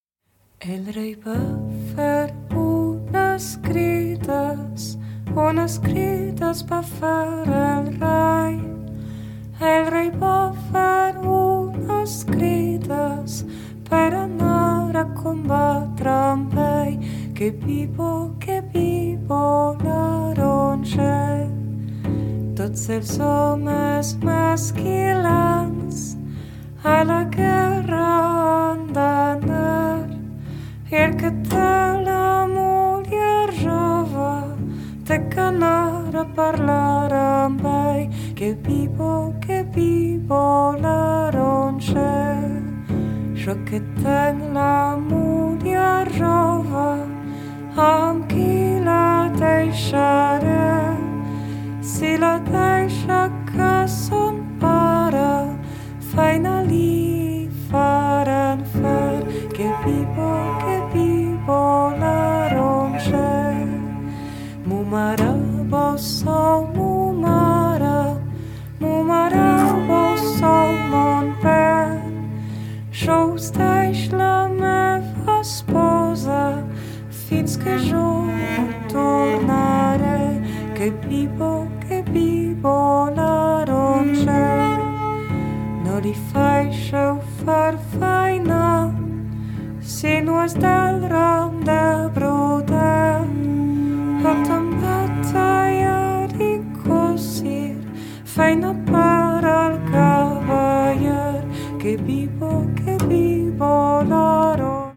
チェロと歌唱！